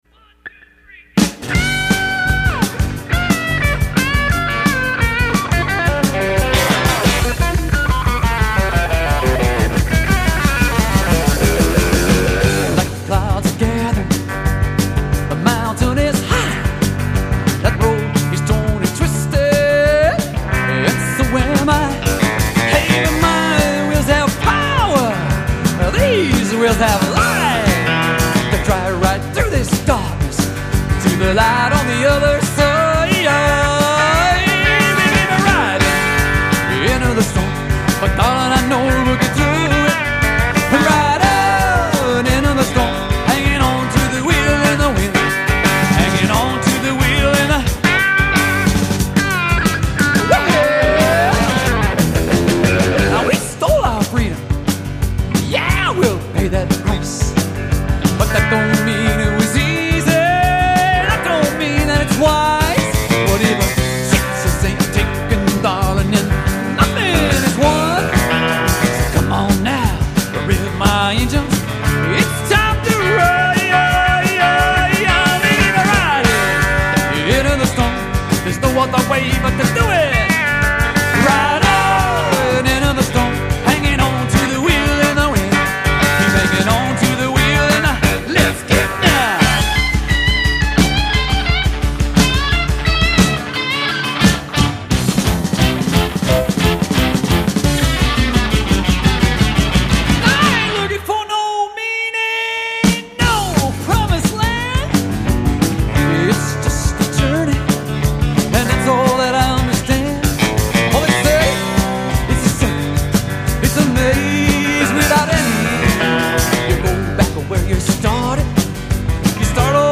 vocals, guitar
Bass
Drums
Telecaster